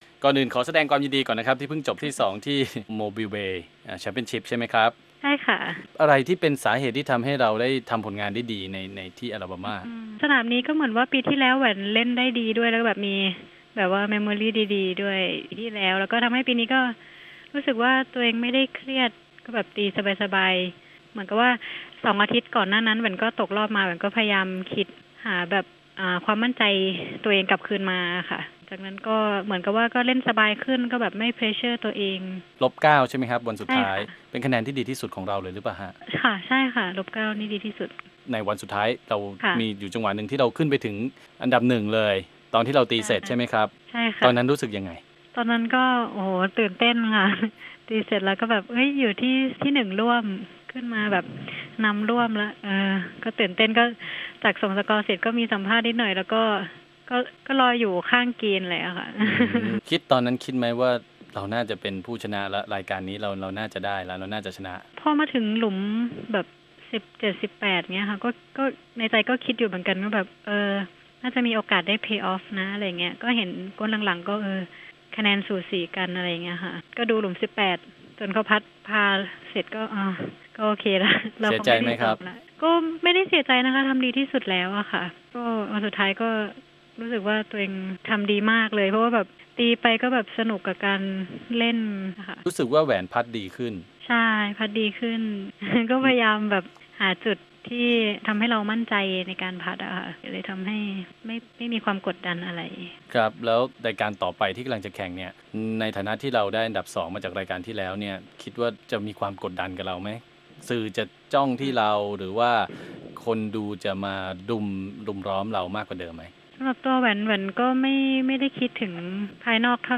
สัมภาษณ์ความรู้สึกโปรแหวน พรอนงค์ เพชรล้ำ หลังพลาดแชมป์รายการ Mobile Bay LPGA Championship เฉียดฉิว